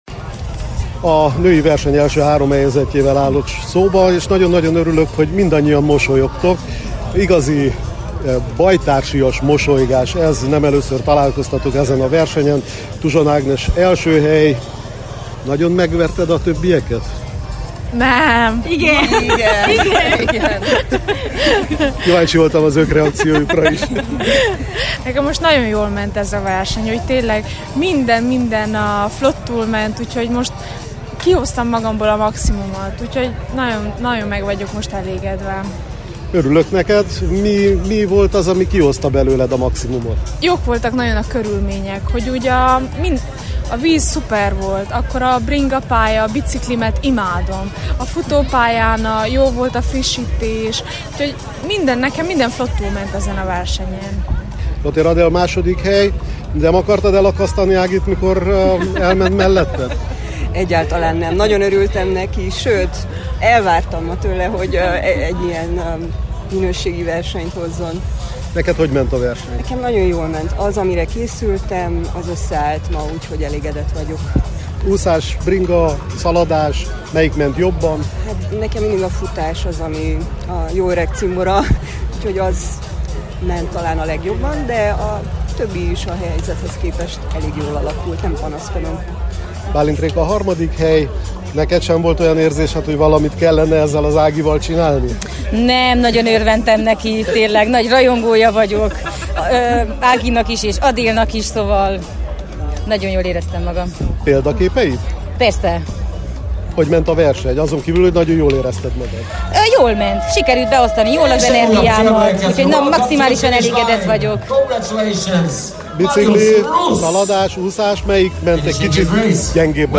készített hosszabb összefoglalót a résztvevőkkel